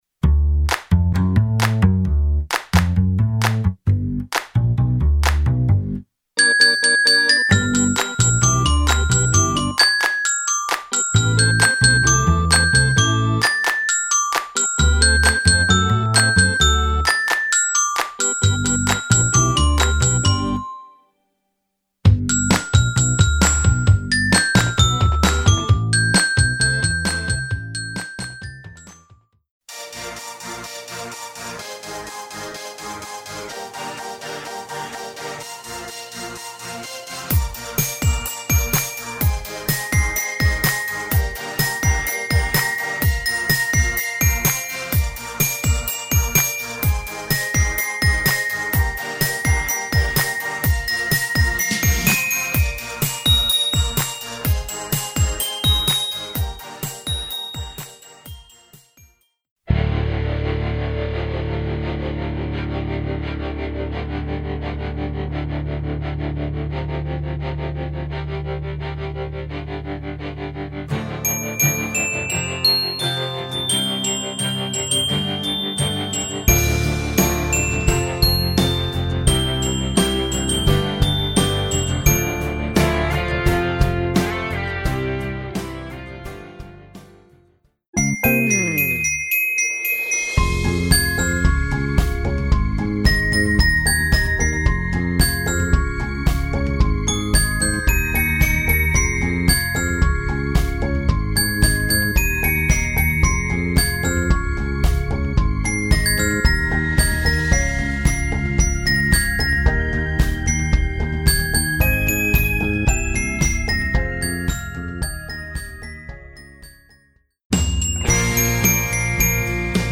Voicing: Mallet